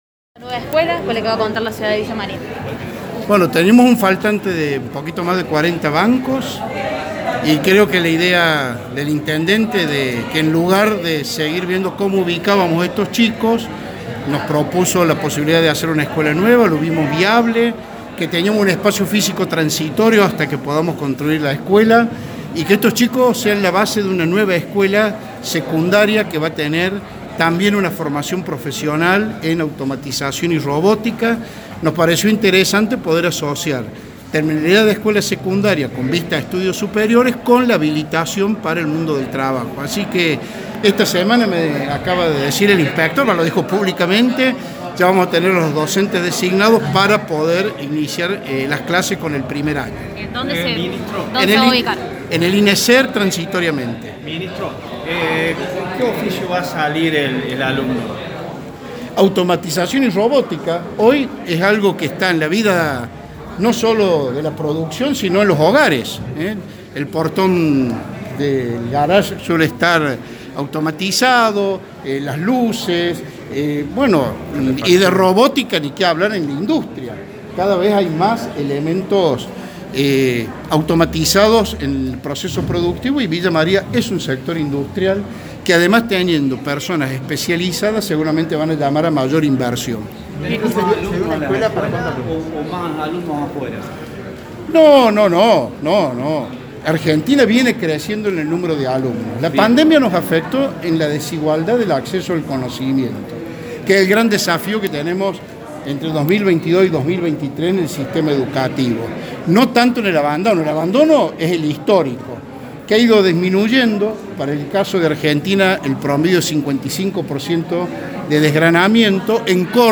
AUDIO – WALTER GRAHOVAC, MINISTRO DE EDUCACIÓN DE CÓRDOBA